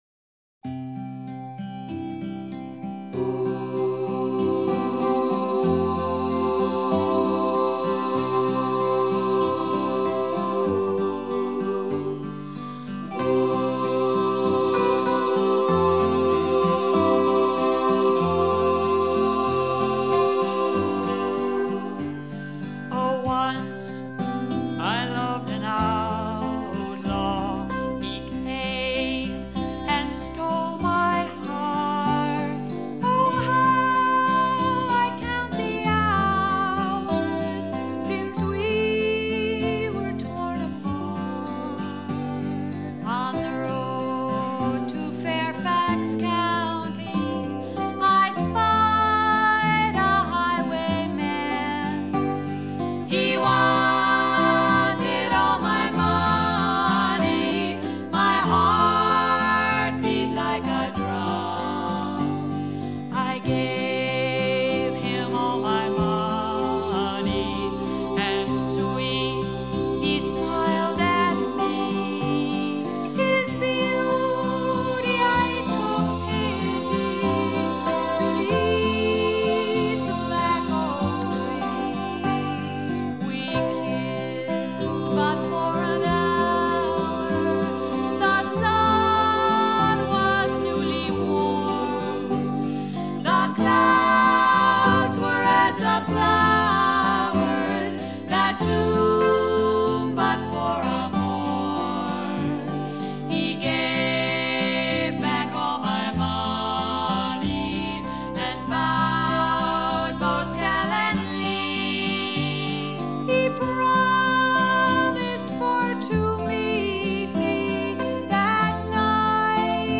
in the tradition of centuries-old English murder ballads